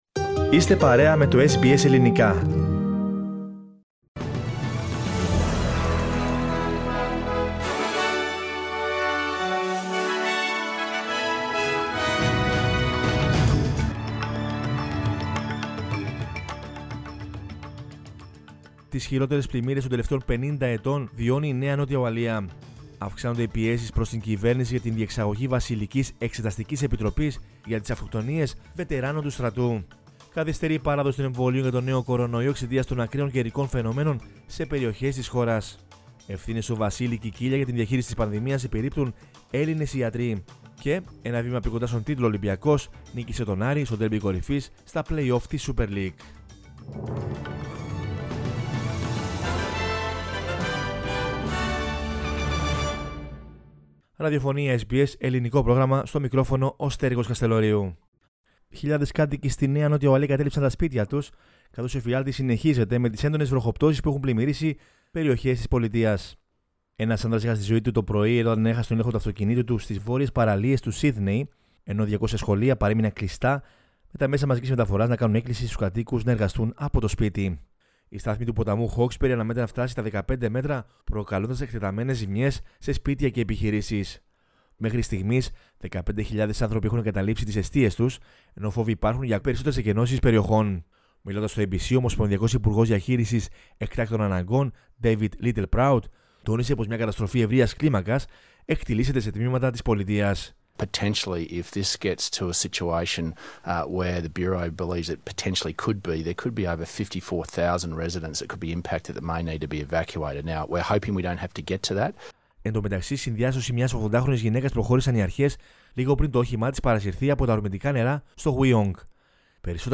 News in Greek from Australia, Greece, Cyprus and the world is the news bulletin of Monday 22 March 2021.